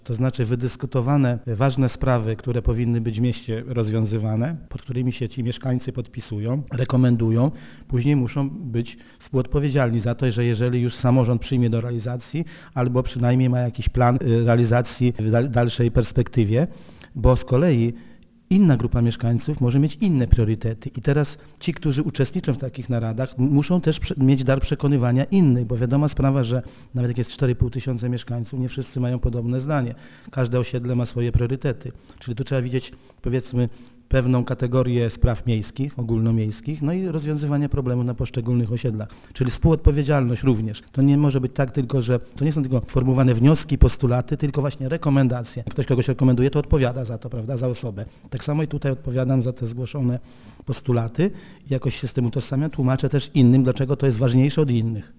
Burmistrz Bodys przypomina, że udział mieszkańców w naradach obywatelskich to nie tylko możliwość wpływania na podejmowane decyzje, ale także współodpowiedzialność za ich realizację: